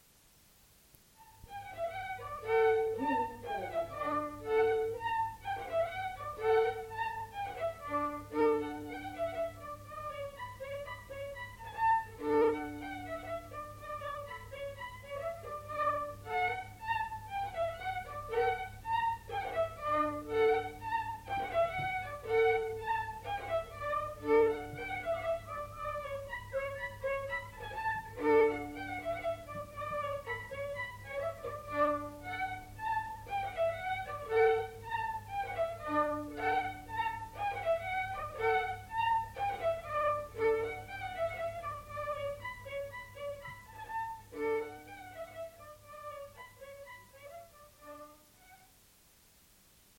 Aire culturelle : Lomagne
Genre : morceau instrumental
Instrument de musique : violon
Danse : polka piquée
Ecouter-voir : archives sonores en ligne